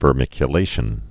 (vər-mĭkyə-lāshən)